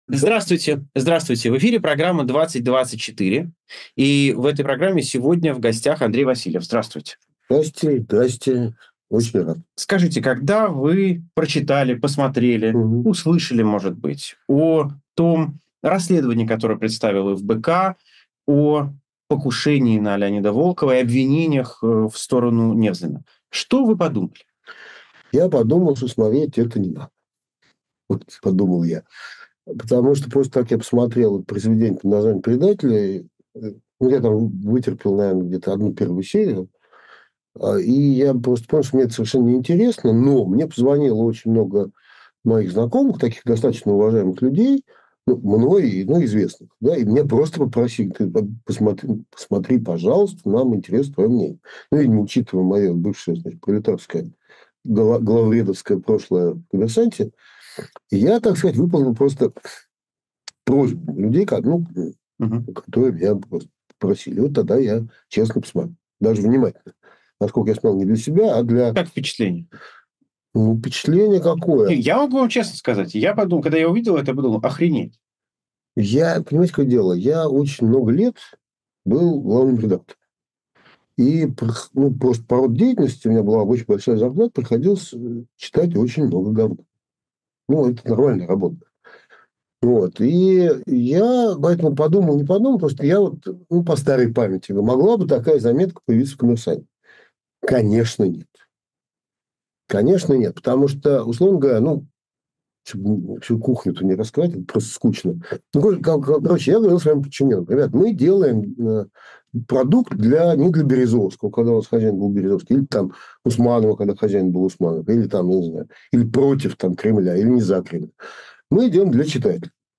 Эфир ведёт Максим Курников